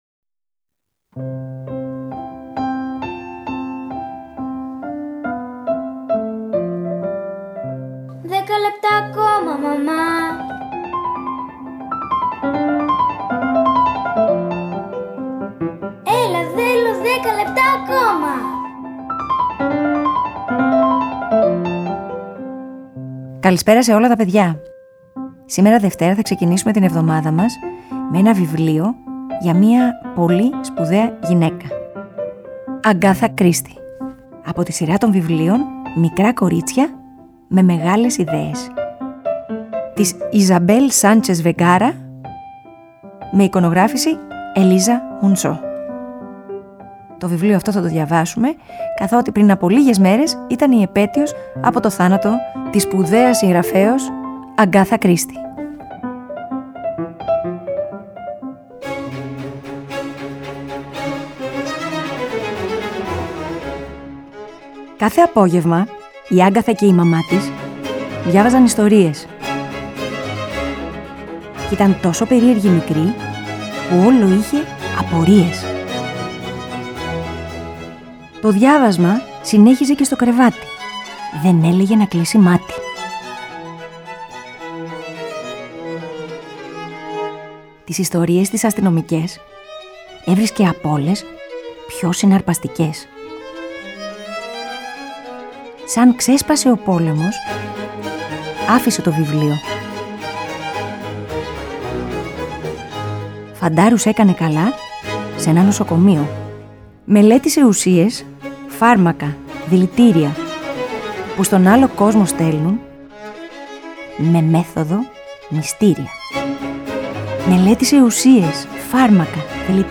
Παιδικα βιβλια ΠΑΡΑΜΥΘΙΑ